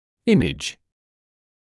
[‘ɪmɪʤ][‘имидж]изображение (в т.ч. фотография, рентгенограмма, КТ и пр.); получать изображения